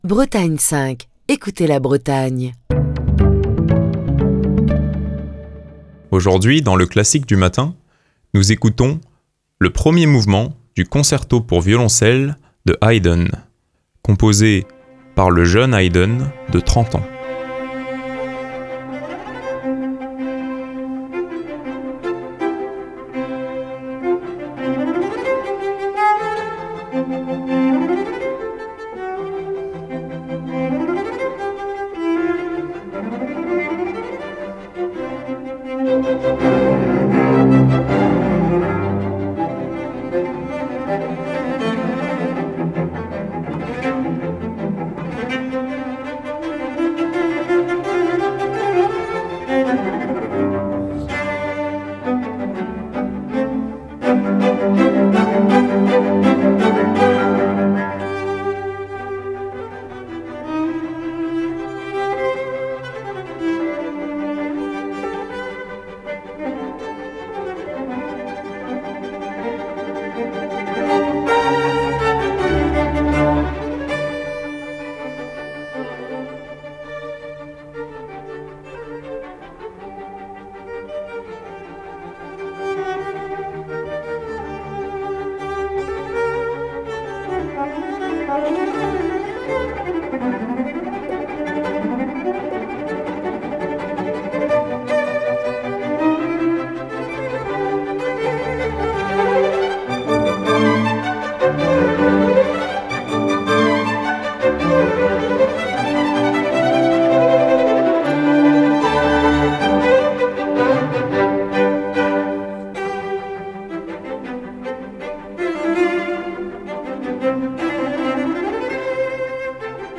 Cette nouvelle semaine du Classique du matin débute avec le concerto pour violoncelle de Joseph Haydn, dans une très belle interprétation de Mstislav Rostropovitch, accompagné par l'Academy of St Martin in the Fields sous la direction de Iona Brown.